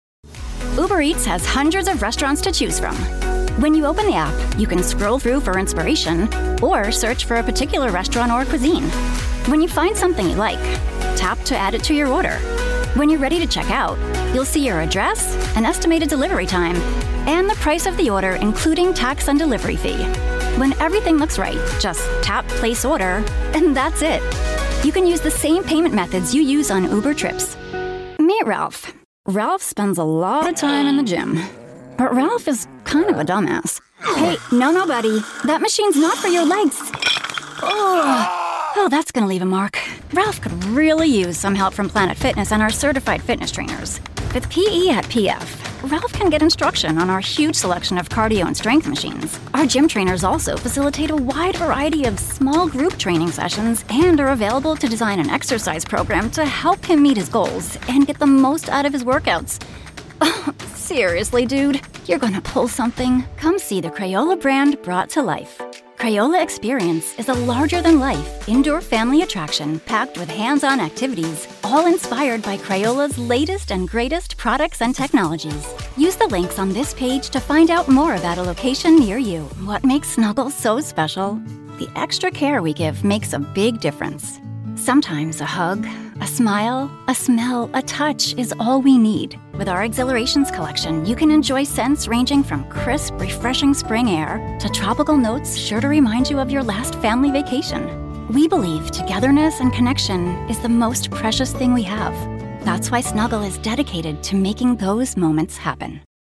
Female
Bright, Bubbly, Friendly, Warm, Confident, Natural, Young, Approachable, Conversational, Energetic, Soft, Upbeat
Microphone: TLM 102, RE20
Audio equipment: Whisper Room Booth, Apollo Twin Interface, DBX 286s preamp/processor, Aventone speakers